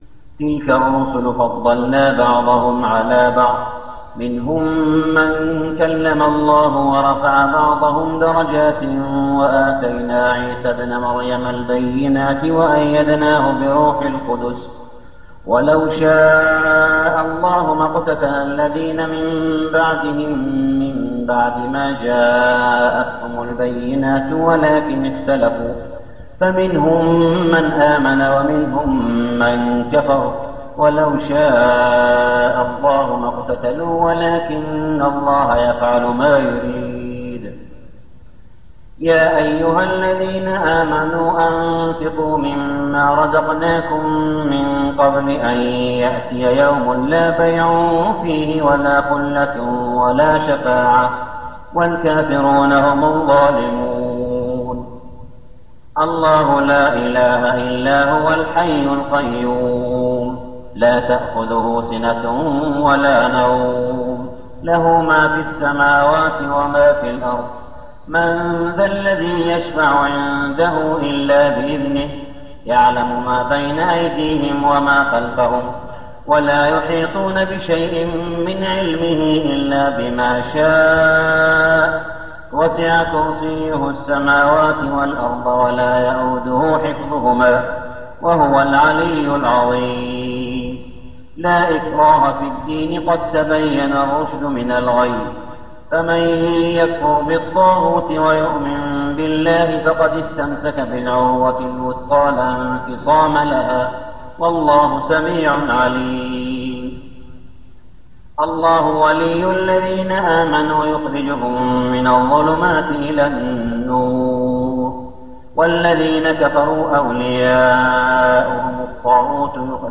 صلاة التراويح ليلة 3-9-1411هـ سورتي البقرة 253-286 و آل عمران 1-17 | Tarawih prayer night 3-9-1411AH Surath Al-Baqarah and Al-Imran > تراويح الحرم المكي عام 1411 🕋 > التراويح - تلاوات الحرمين